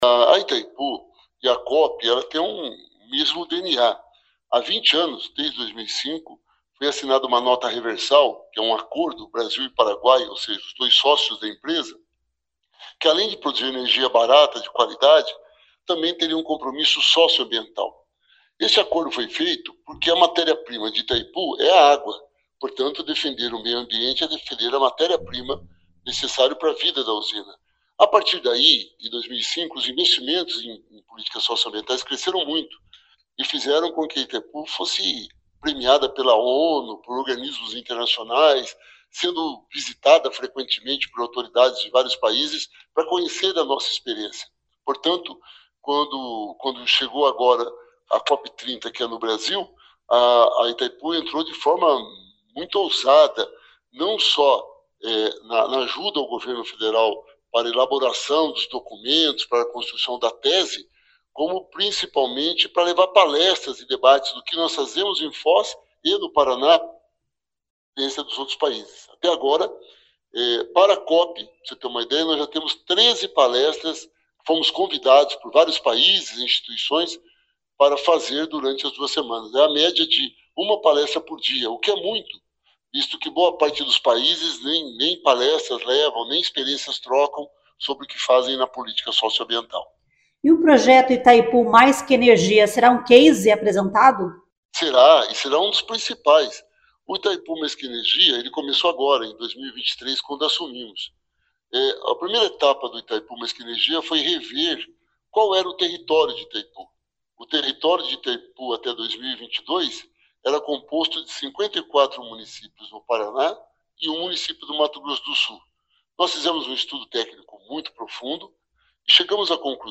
Ele explica sobre a relação intrínseca entre a COP e a Itaipu Binacional.